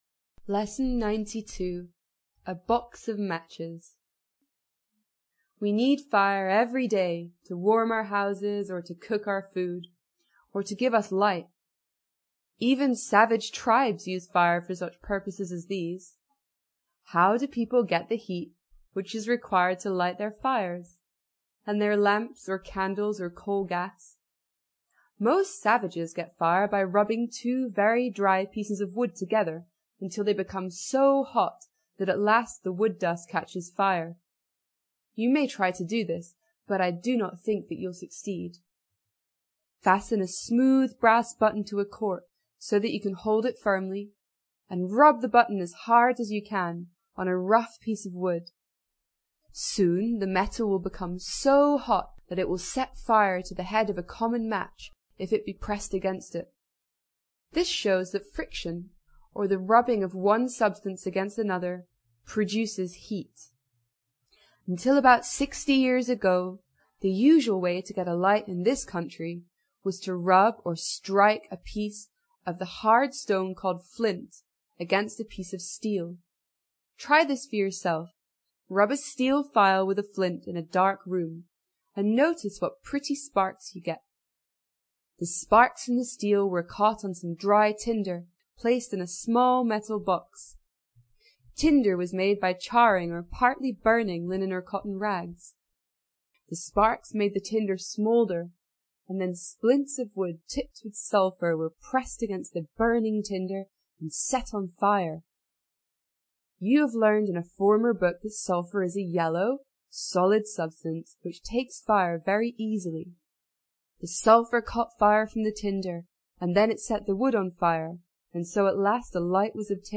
在线英语听力室英国学生科学读本 第92期:一盒火柴(1)的听力文件下载,《英国学生科学读本》讲述大自然中的动物、植物等广博的科学知识，犹如一部万物简史。在线英语听力室提供配套英文朗读与双语字幕，帮助读者全面提升英语阅读水平。